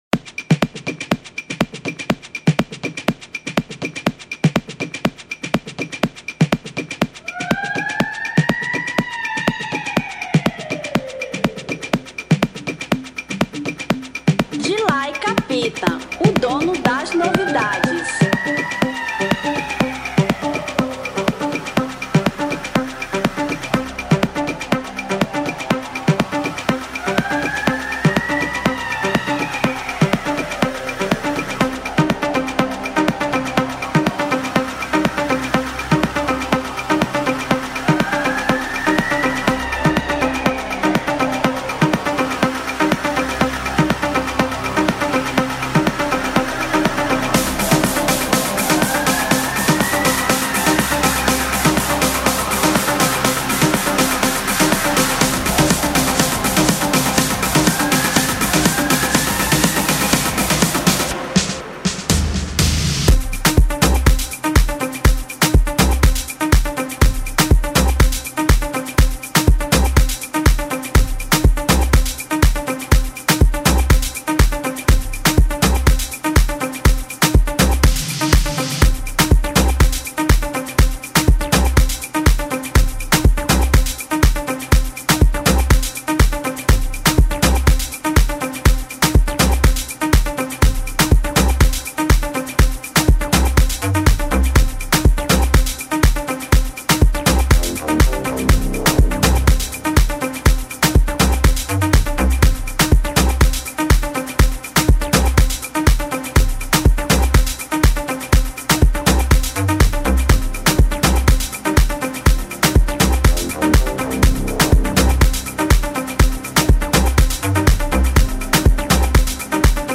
Original Mix 2025